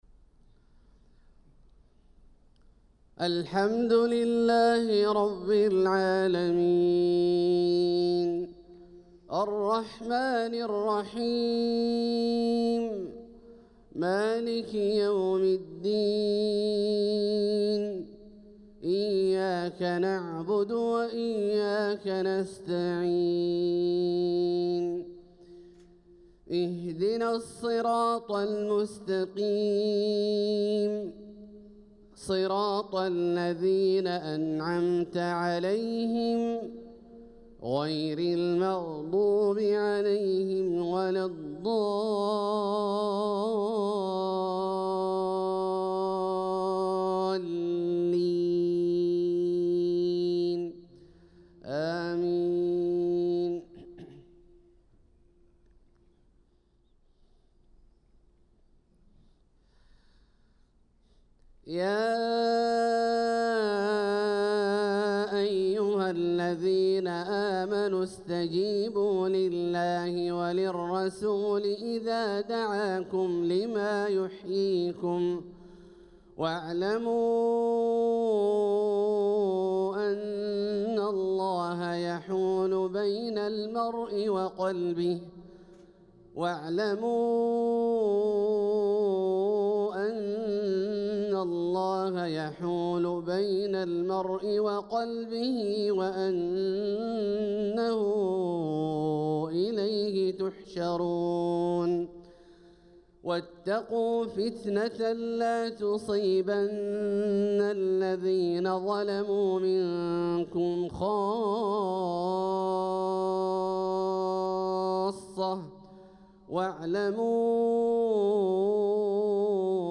صلاة الفجر للقارئ عبدالله الجهني 9 ربيع الأول 1446 هـ